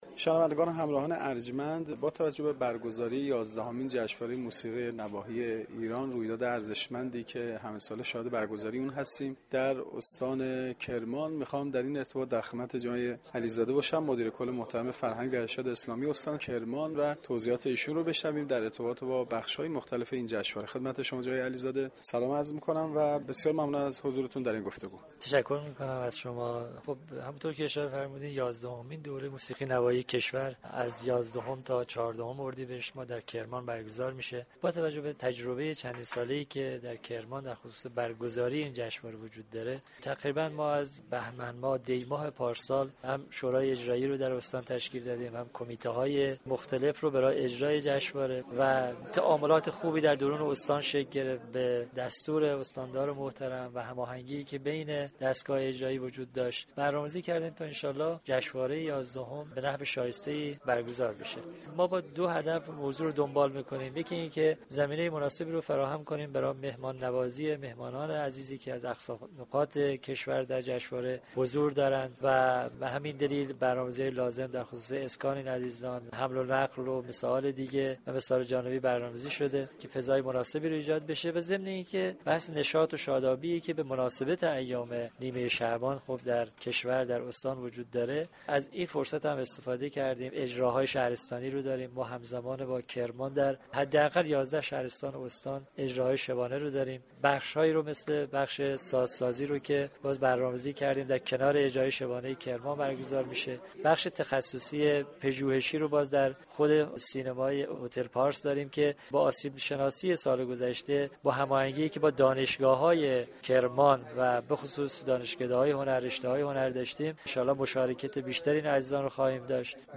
محمد رضا علیزاده مدیر كل فرهنگ و ارشاد استان كرمان در گفتگو با گزارشگر رادیو فرهنگ